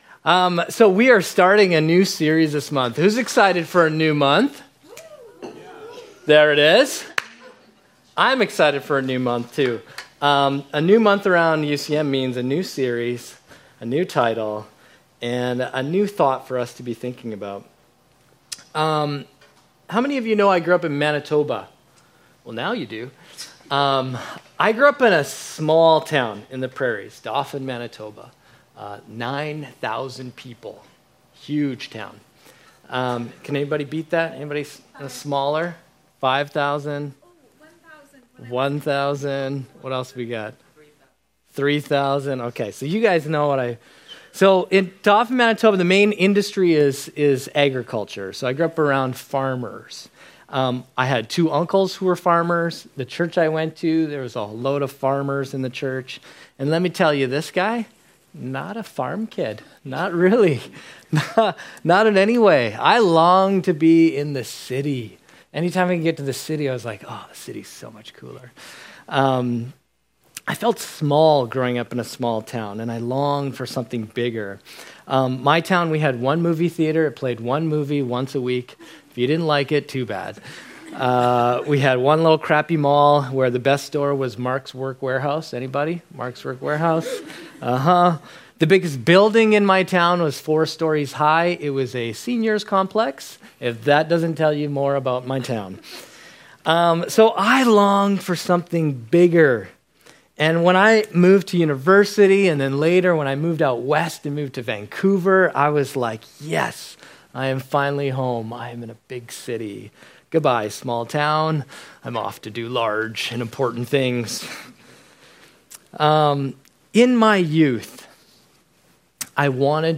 But Jesus calls us to follow him into a new way of living - the Jesus way. Thursday Nights is our weekly worship gathering where we engage teachings from the Bible around relevant topics for university students.